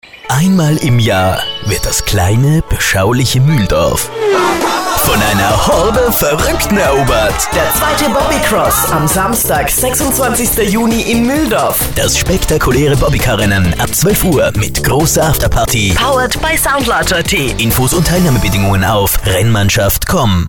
Radiospot [mp3 0.5MB]